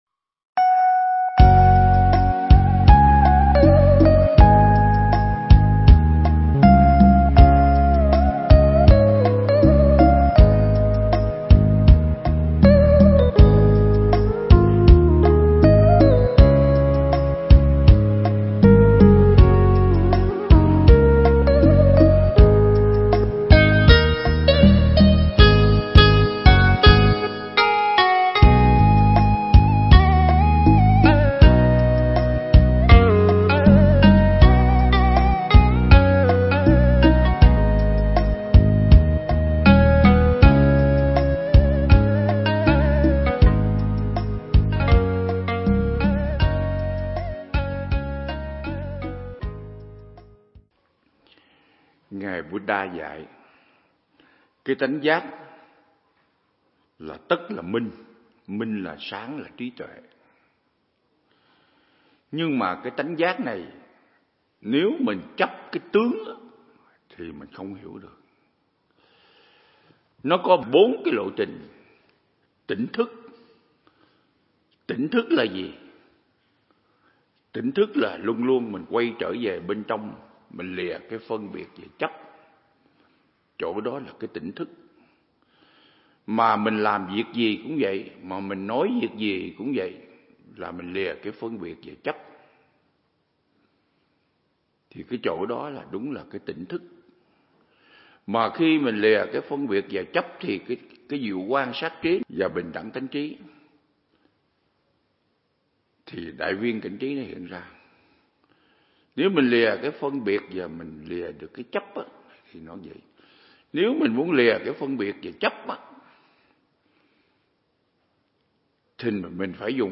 Mp3 Pháp Thoại Triết Lý Thủ Lăng Nghiêm Phần 16
giảng tại Viện Nghiên Cứu Và Ứng Dụng Buddha Yoga Việt Nam